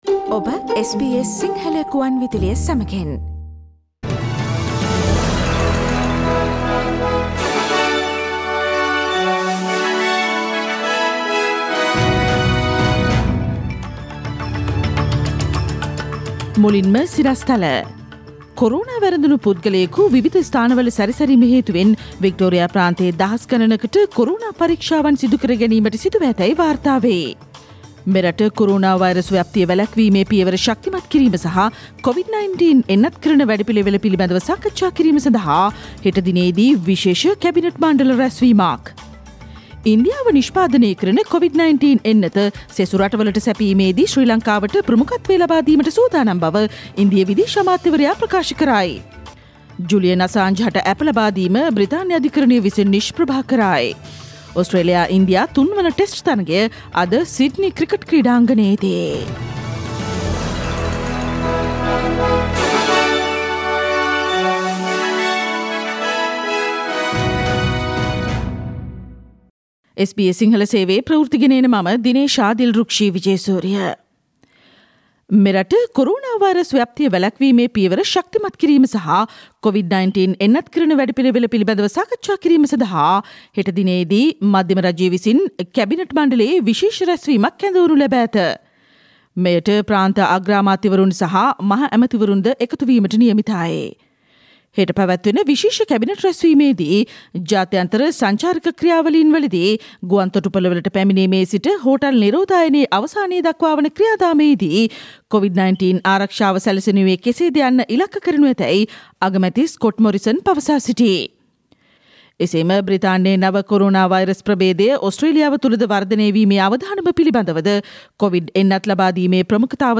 Today’s news bulletin of SBS Sinhala radio – Thursday 7 January 2021.